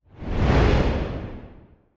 powerball_change.mp3